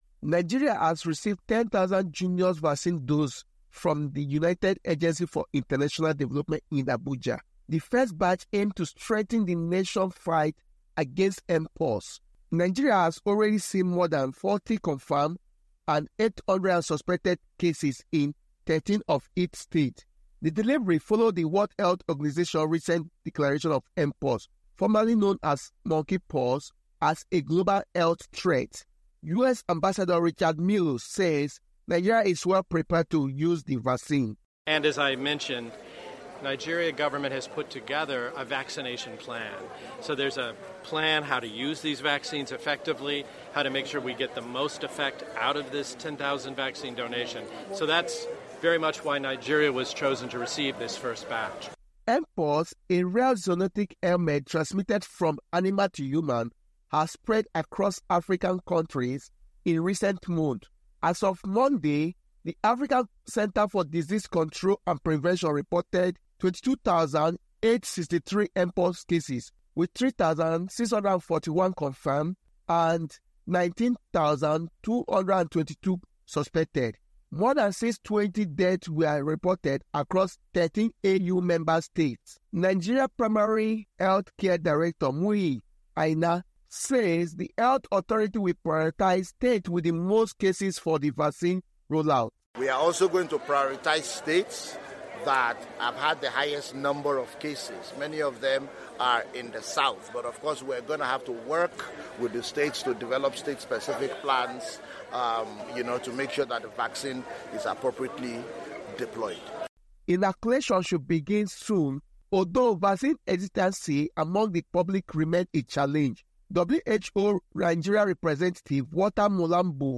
reports from Abuja